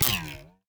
11004 broken string bounce.ogg